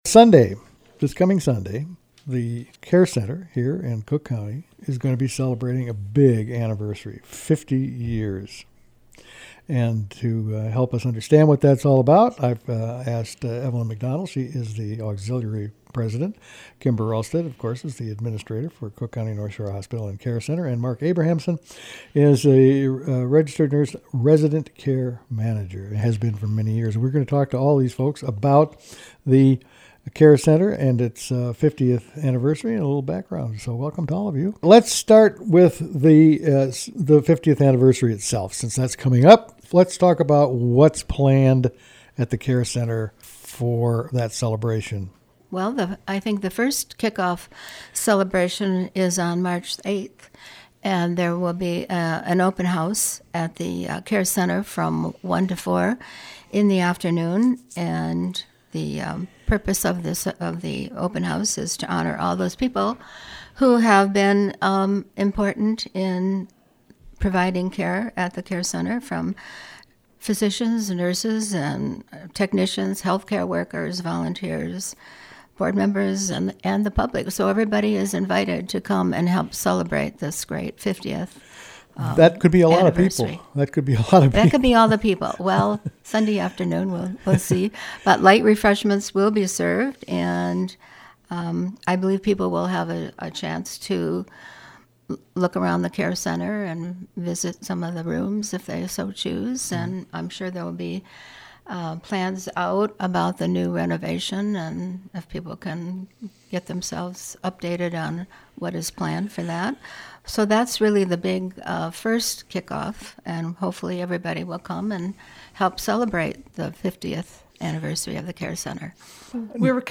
We’ll talk with three people intimately associated with long term care in Cook County.